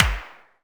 clapOnbeat3.ogg